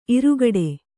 ♪ arugaḍe